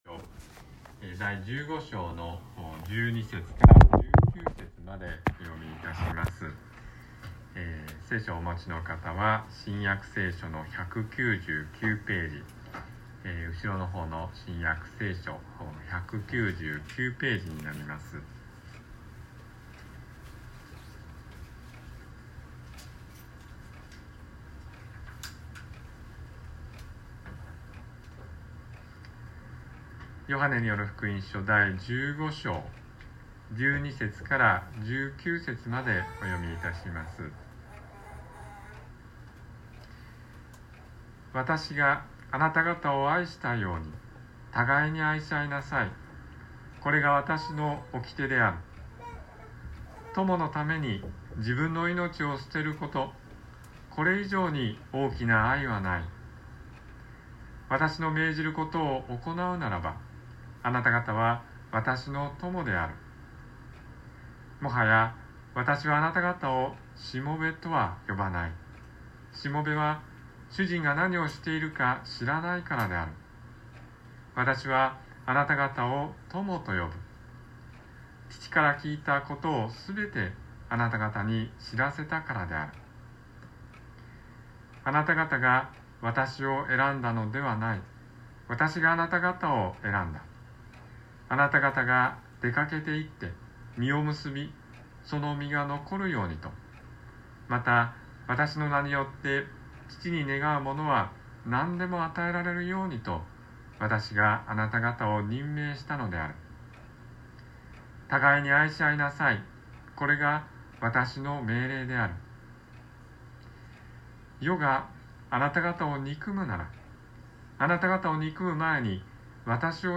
宝塚の教会。説教アーカイブ。